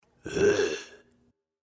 zombie-grunt.mp3